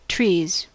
Ääntäminen
Ääntäminen : IPA : /tɹiːz/ US : IPA : [tɹiːz] Haettu sana löytyi näillä lähdekielillä: englanti Käännöksiä ei löytynyt valitulle kohdekielelle. Trees on sanan tree monikko.